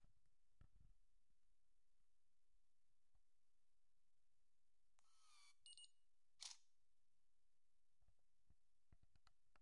相机快门速度
描述：相机快门操作速度快
标签： 相机 快门
声道立体声